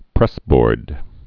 (prĕsbôrd)